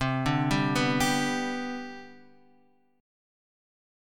CM7sus2 chord